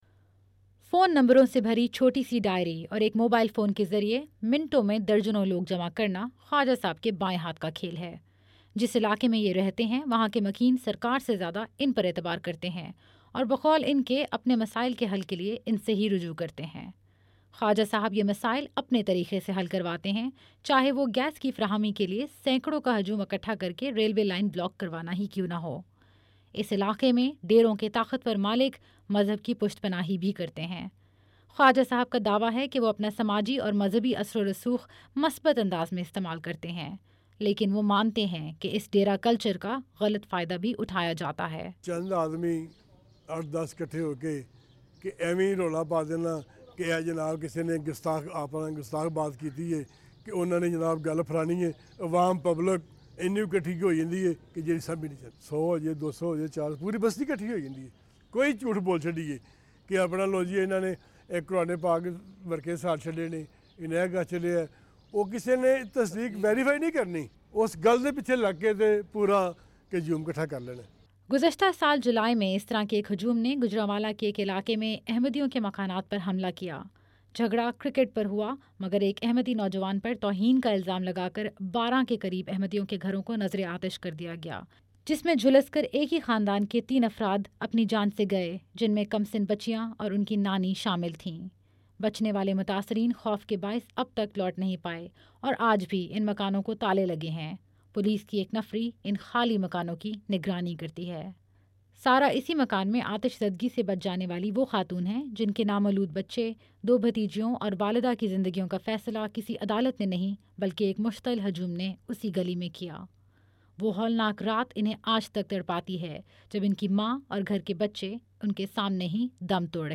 کرائے کے ہجوم کا کاروبار: آڈیو رپورٹ